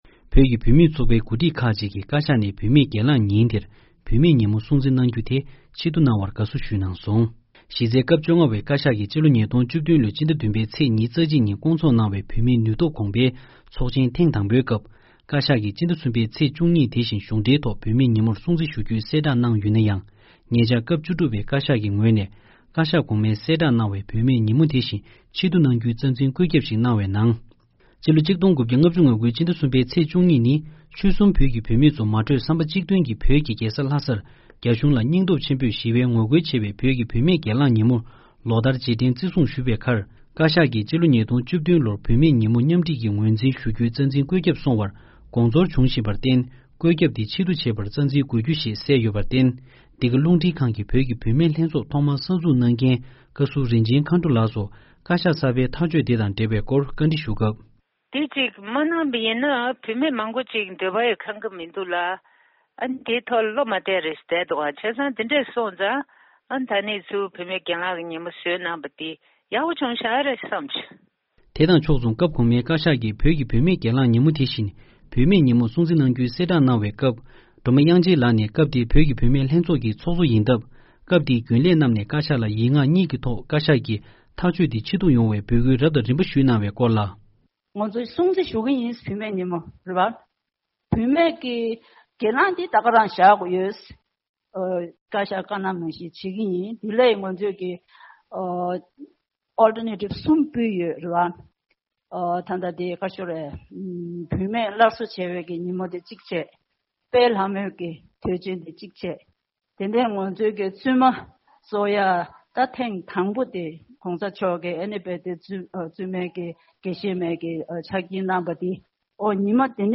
༄༅།། བོད་ཀྱི་བུད་མེད་ལྷན་ཚོགས་ཀྱི་འགོ་ཁྲིད་ཚོས་བཀའ་ཤག་ནས་ཕྱི་ཟླ་༣་པའི་ཚེས་༡༢་ནི་བོད་ཀྱི་བུད་མེད་སྒེར་ལངས་ཉིན་མོར་སྲུང་བརྩི་ཞུ་བའི་དུས་དྲན་སླར་གསོ་བྱ་རྒྱུའི་ཐག་གཅོད་ལ་དགའ་བསུ་ཞུས་འདུག་པའི་སྐོར། བཞུགས་སྒར་རྡ་རམ་ས་ལ་ནས་འདི་གའི་གསར་འགོད་པ